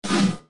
Download Half Life Crowbar Swing sound effect for free.
Half Life Crowbar Swing